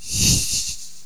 snake_attack3.wav